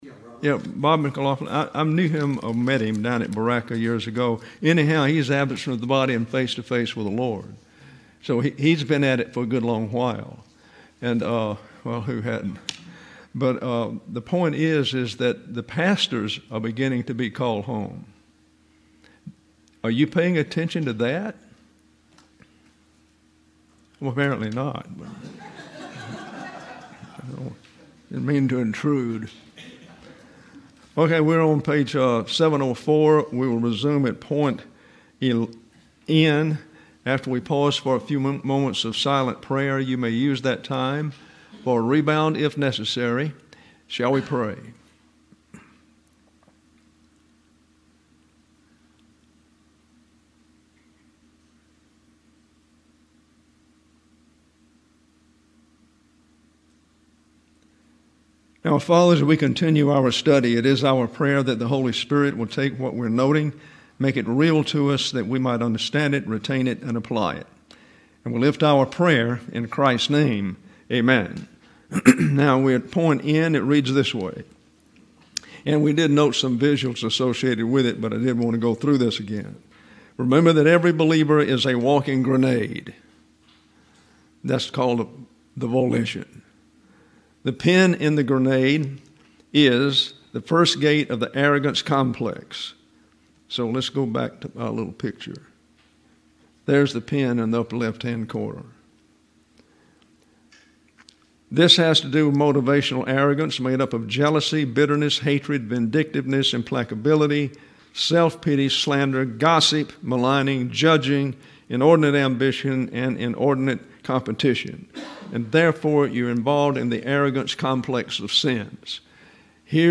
James Chapter Four: Lesson 237: Doctrine of Fragmentation: Human Relationship Fragmentation; Christian Fellowship;